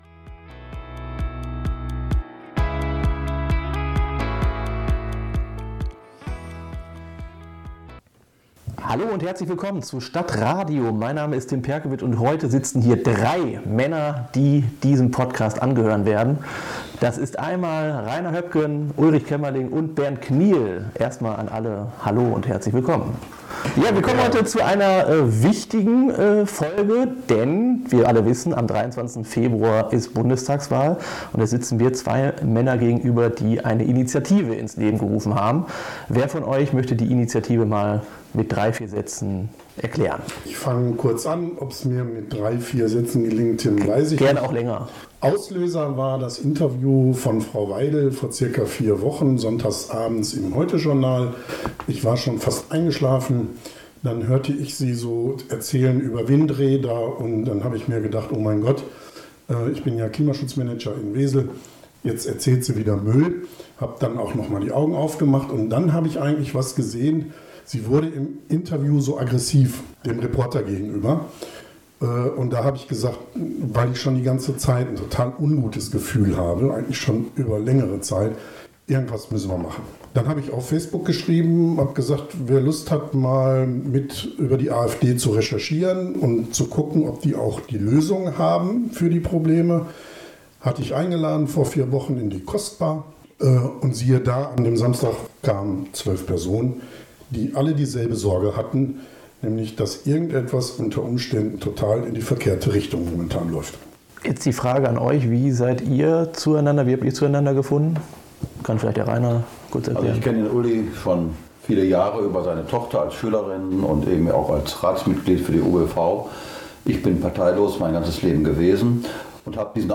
WIR BITTEN DIE SCHLECHTE TONQUALITÄT ZU ENTSCHULDIGEN!